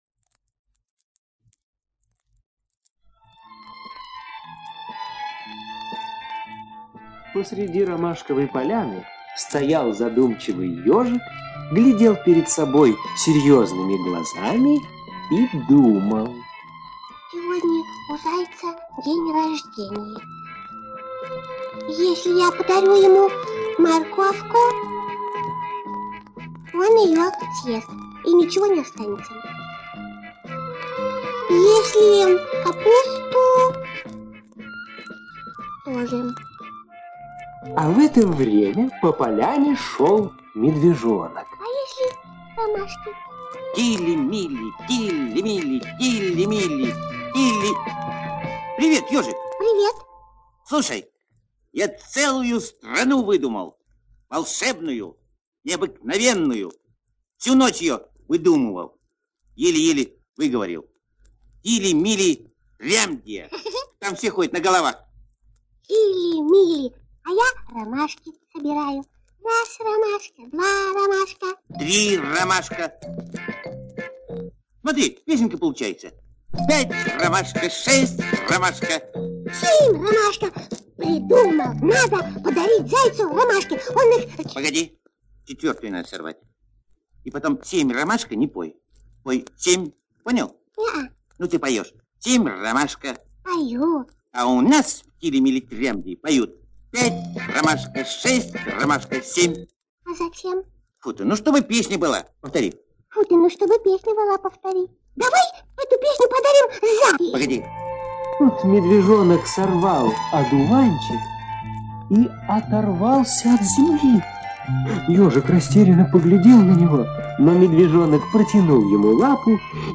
Трям! Здравствуйте! – Козлов С.Г. (аудиоверсия)